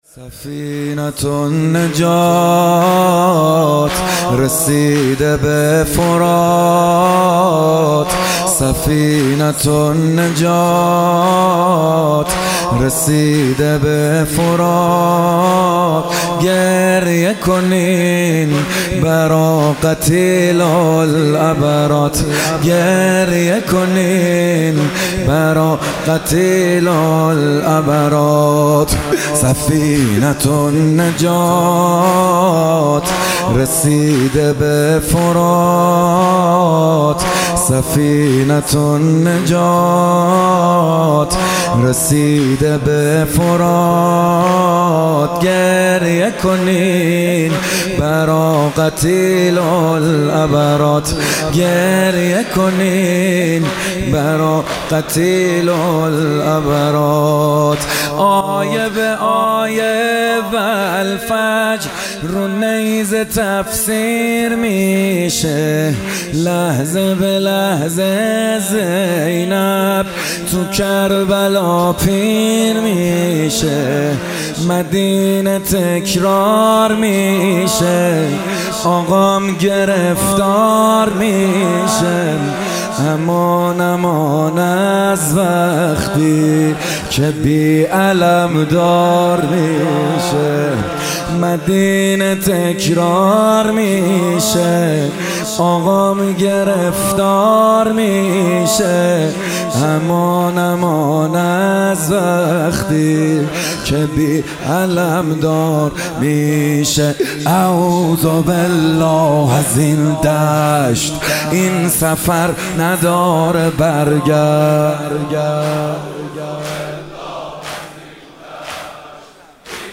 دومین روز از مـــراســم عـــزاداری دهــه اول مـــحــرم الـحــرام در مهدیه امام حسن مجتبی(ع